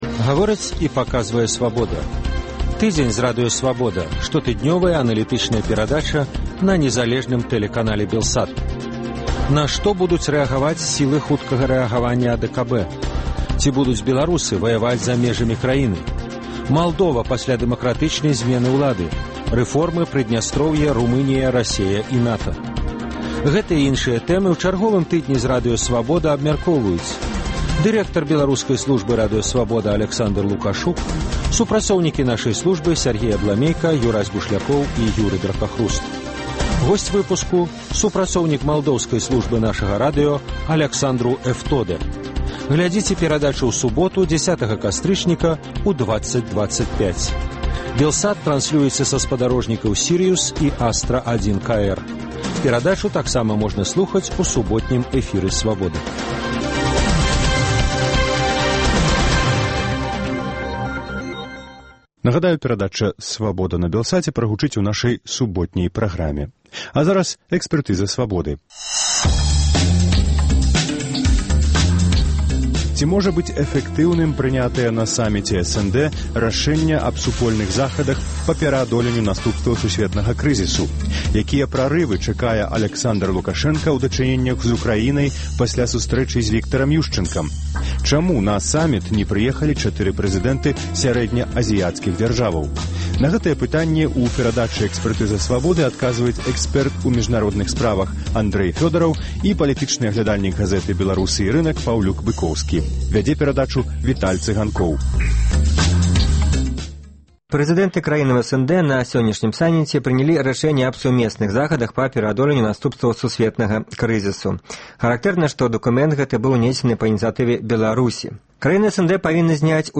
Ці можа быць эфэктыўным прынятая на саміце СНД рашэньне аб сумесных захадах па пераадоленьню наступстваў сусьветнага крызісу? Якія прарывы чакае Аляксандар Лукашэнка ў дачыненьнях у Украінай пасьля сустрэчы з Віктарам Юшчанкам? Чаму на саміт не прыехалі 4 прэзыдэнты сярэднеазіяцкіх дзяржаваў? Гэтыя пытаньні абмяркоўваюць экспэрт у міжнародных справаў